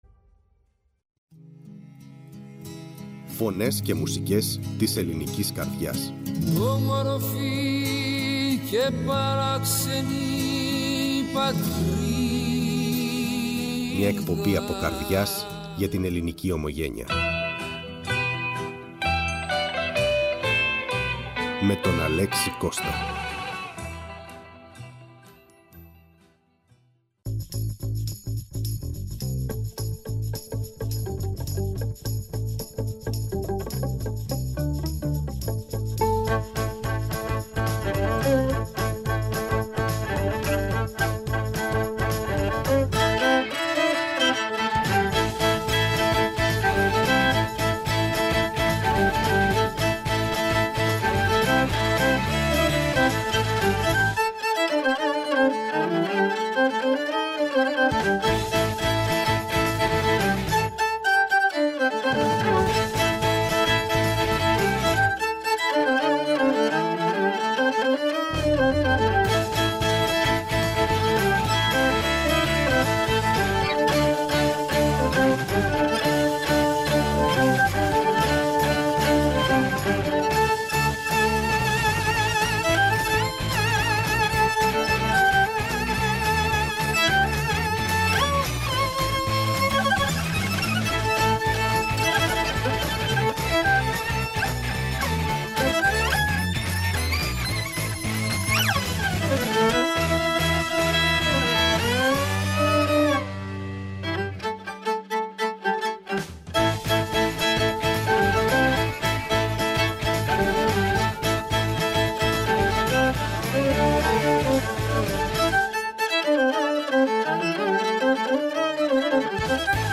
Ακούστε όλη τη συνέντευξη στο ηχητικό της ανάρτησης Η ΦΩΝΗ ΤΗΣ ΕΛΛΑΔΑΣ Φωνες και Μουσικες ΟΜΟΓΕΝΕΙΑ Πολιτισμός Συνεντεύξεις